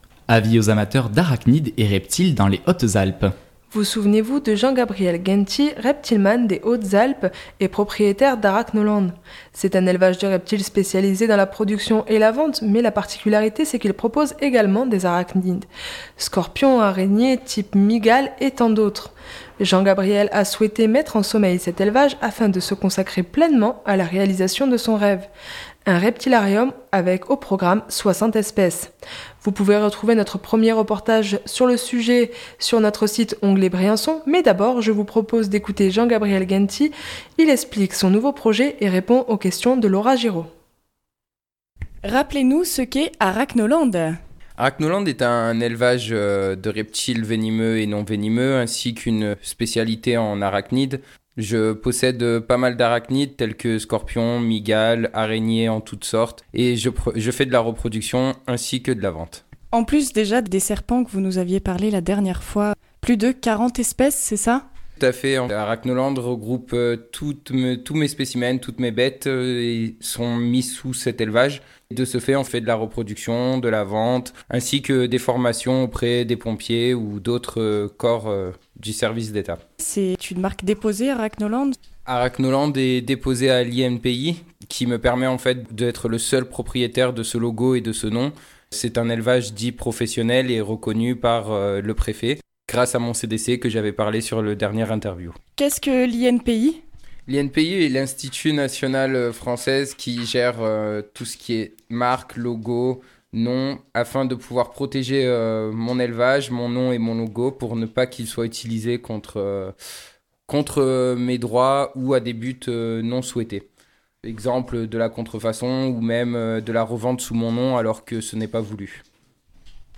il explique son nouveau projet et répond aux questions